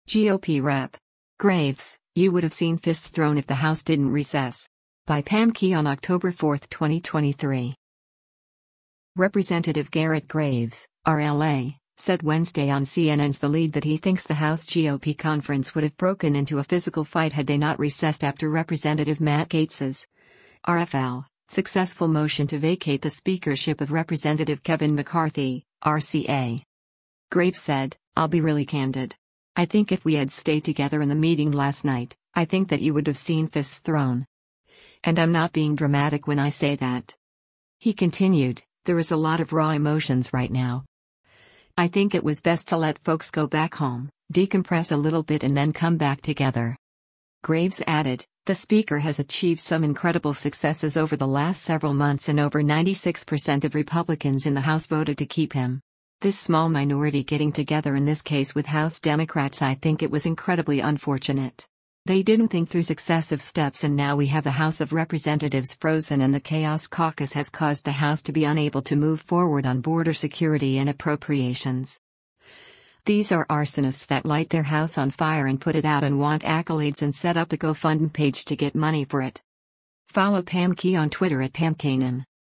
Representative Garret Graves (R-LA) said Wednesday on CNN’s “The Lead” that he thinks the House GOP conference would have broken into a physical fight had they not recessed after Rep. Matt Gaetz’s (R-FL) successful motion to vacate the speakership of Rep. Kevin McCarthy (R-CA).